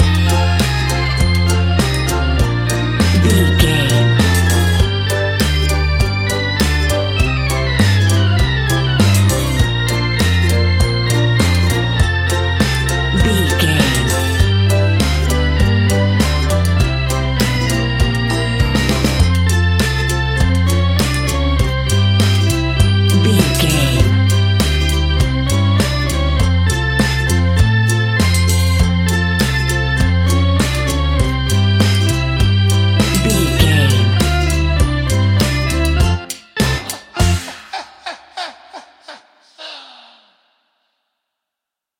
In-crescendo
Thriller
Aeolian/Minor
scary
ominous
dark
eerie
spooky
horror music
Horror Pads
horror piano
Horror Synths